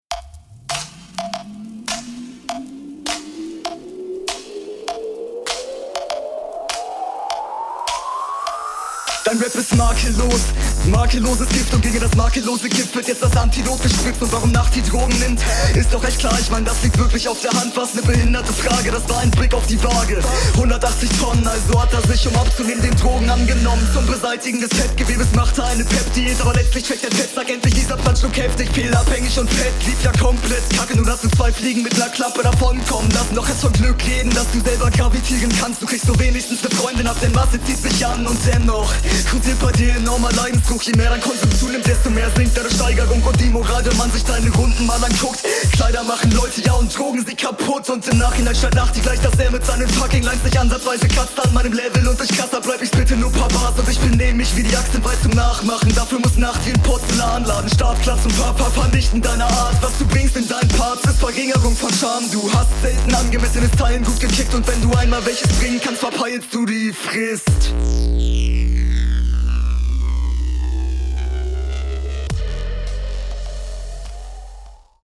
geiler einstieg! erste mal dass ich hier so nen dubstep angehauchten beat höre. sehr souveräner …
Dope gerappt, Mische ist auch gyle, Stimmeinsatz ist druckvoll und passt, nun zu den Lines, …